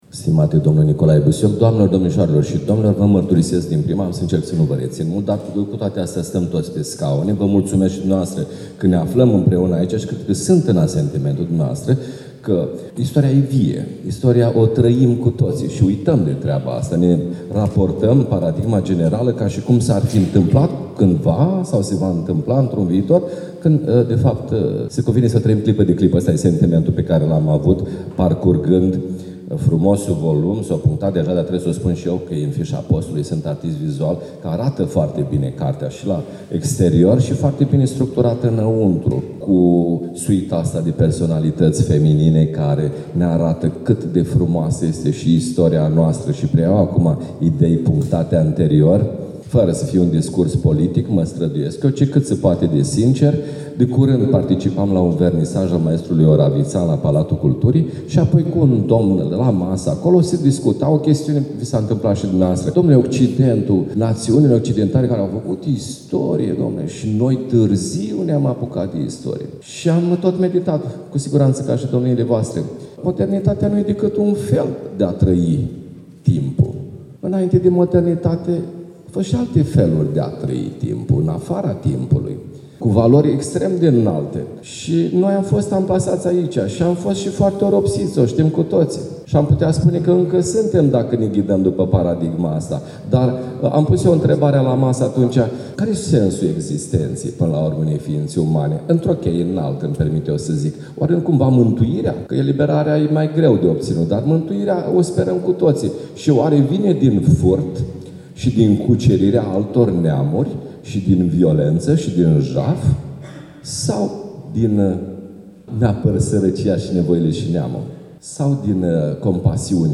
La manifestarea de zilele trecute de la Iași, din Aula Bibliotecii Centrale Universitare „Mihai Eminescu”, am urmărit și discursul